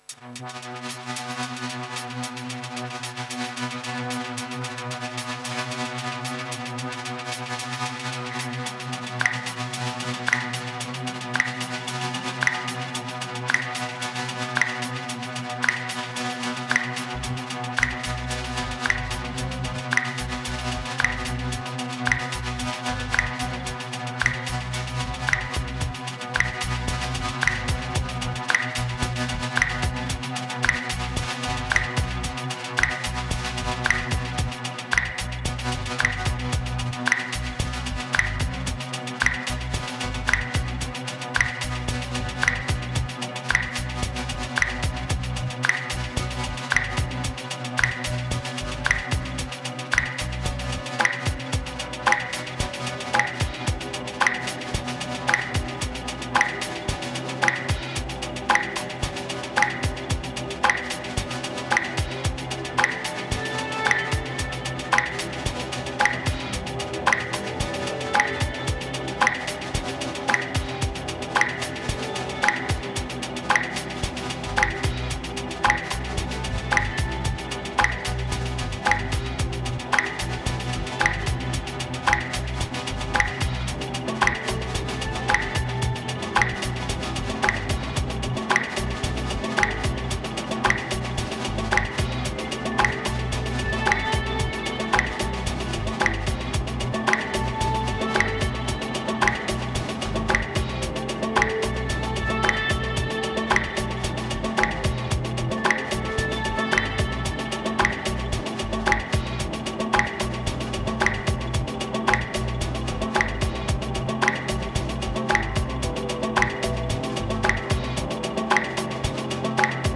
Dark clouds in a winter cold morning.